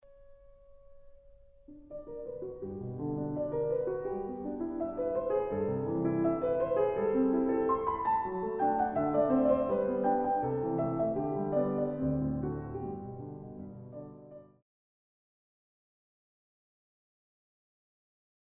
Iedere keer moet er ergens wat met het metrum gebeuren om het weer te laten kloppen, maar dat wordt nooit als een maatwisseling genoteerd. Luister naar deze vier momenten in de expositie, doorwerking, nog eens doorwerking en tenslotte in de reprise.